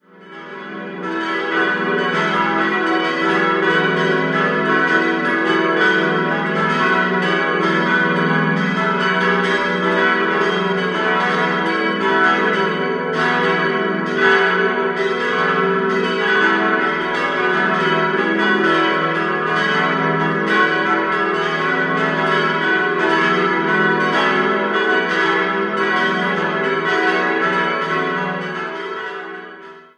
Friedensglocke e' 1.050 kg 123 cm 1958 Gebhard, Kempten Michaelsglocke fis' 780 kg 108 cm 1958 Gebhard, Kempten Marienglocke gis' 450 kg 89 cm 1815 unbezeichnet ???-glocke h' 300 kg 82 cm 1958 Gebhard, Kempten Marienglocke cis'' 280 kg 75 cm 1815 unbezeichnet